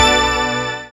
SYN DANCE0FR.wav